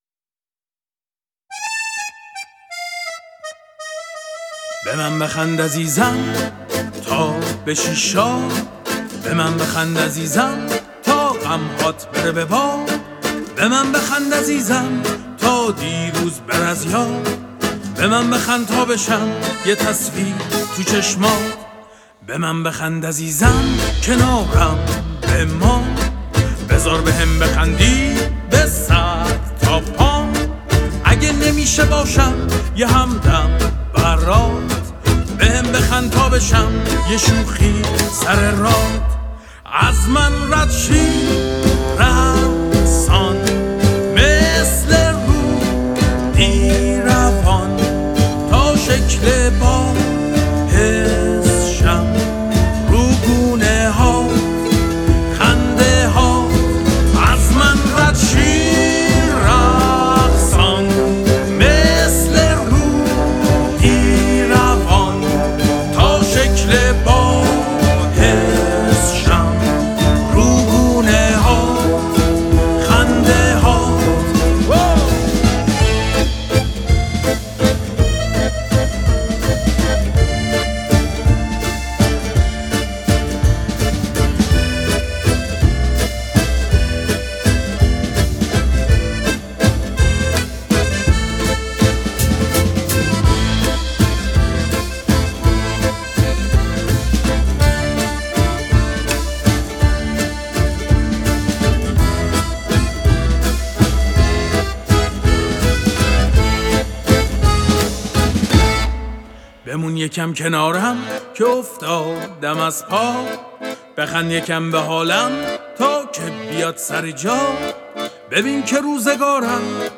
گیتار ریتم